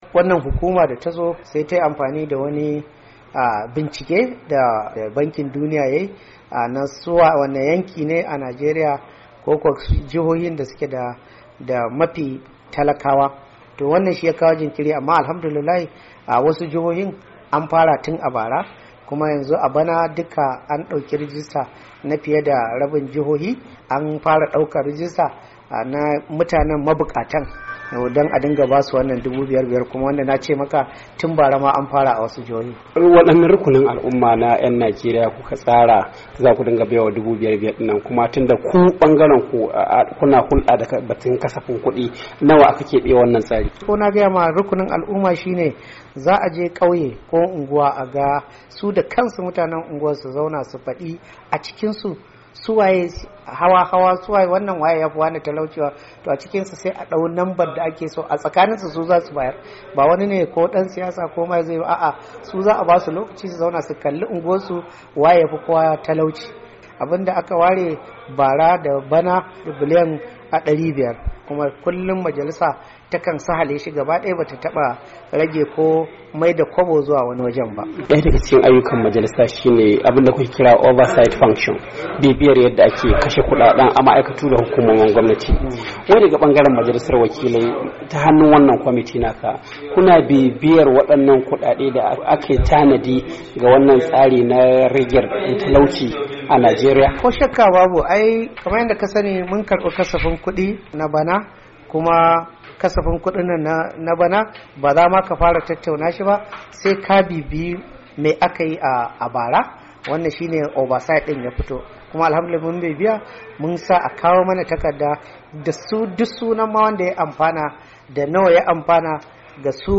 A hirar da sashen Hausa yayi da, shugaban kwamitin yaki da fatara na majalisar wakilan Najeriya Honarable Mohammed Ali Wudil, yace kwamitin sa na nazarin kudaden da gwamnati ta kebewa tsarin a shekarar kudi ta 2017.